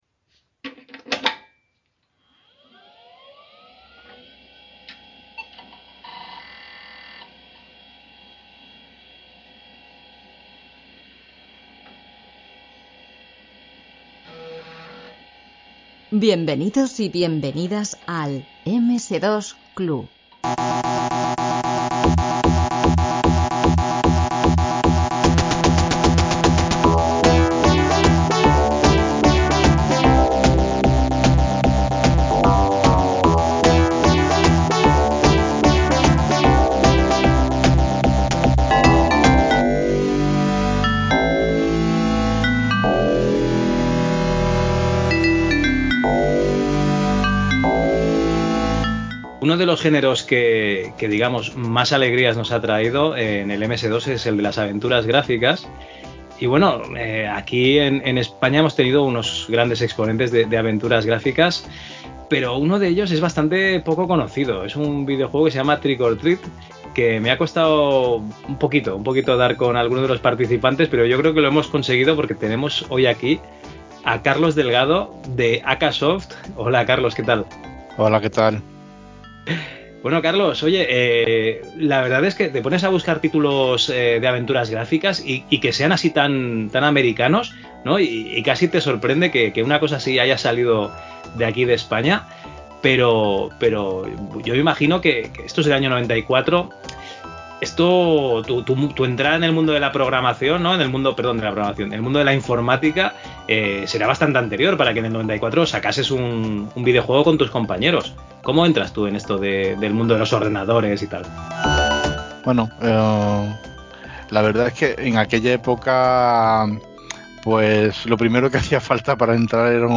Este programa fue grabado hace bastante tiempo y ha sido recuperado de un disco duro que falló por lo que puede que el contexto no coincida con la actualidad, pero como hablamos de juegos de hace 30 años el cuerpo de la entrevista se entenderá perfectamente.